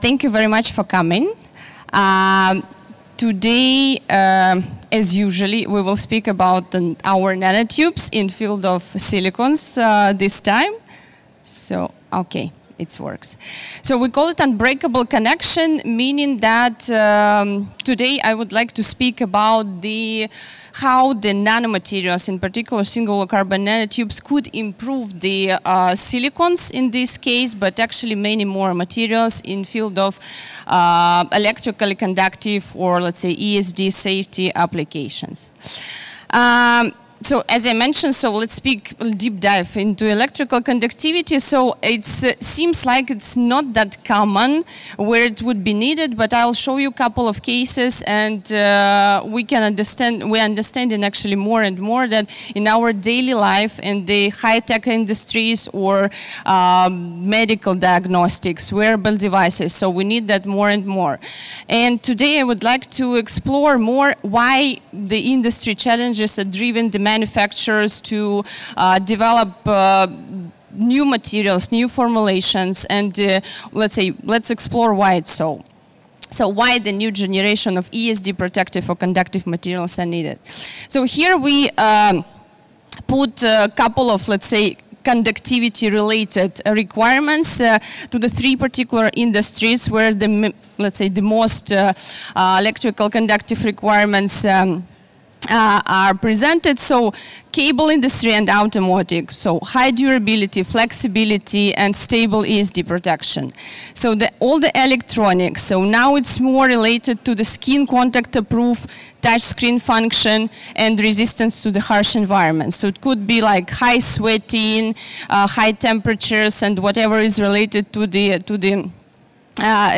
Materials Week Europe 2026.
Presentation